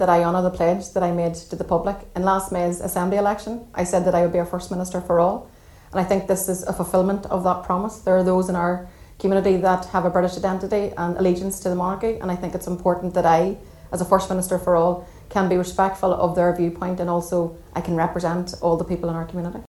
First Minister designate Michelle O’Neill outlines her reason for attending the event: